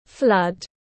Flood /flʌd/